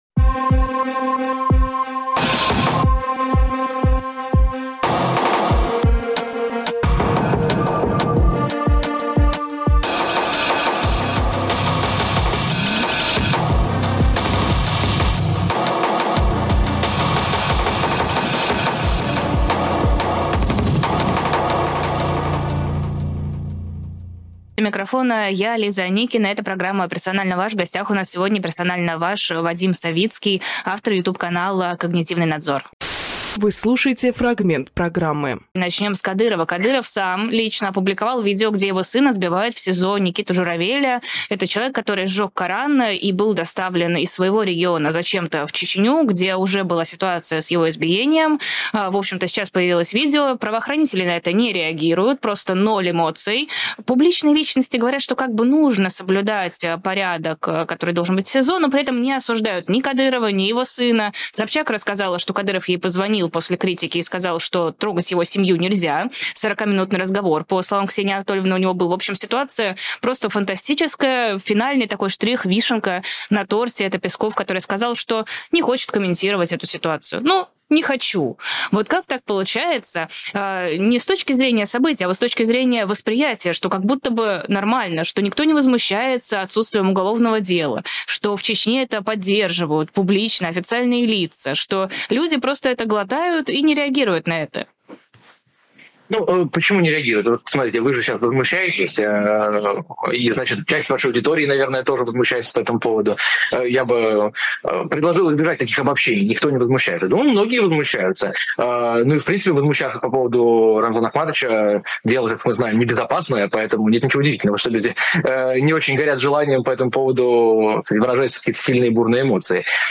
Фрагмент эфира от 26.09